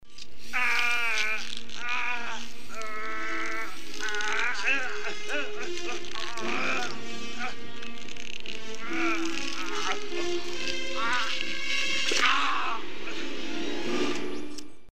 Eaaugeaeagheagh!
A killer display of animatronics work combined with Arnold's
trademark grunting noises easily makes this the most memorable scene in the entire movie if you ask me.